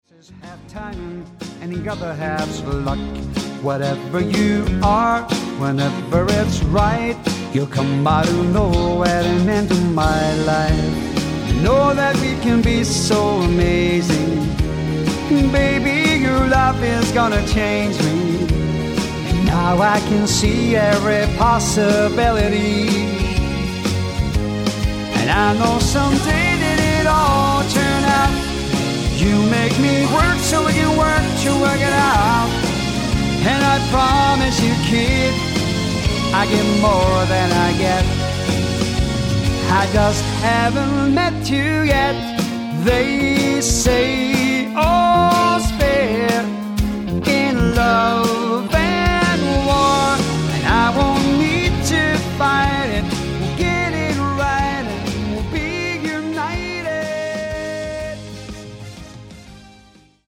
swing
Tribute Music Samples